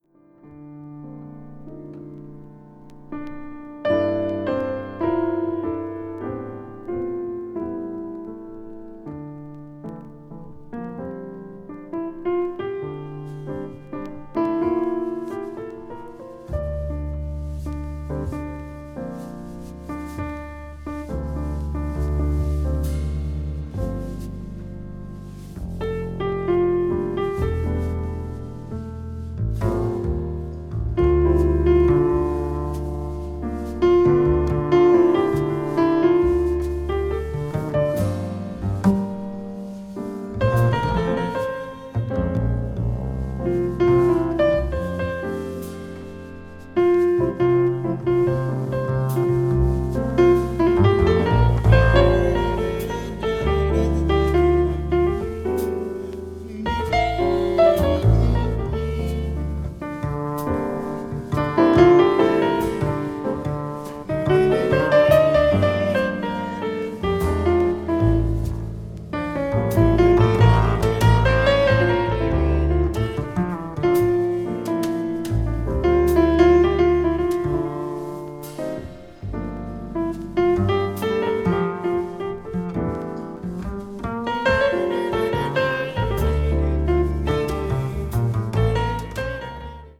media : EX+/EX+(わずかにチリノイズが入る箇所あり)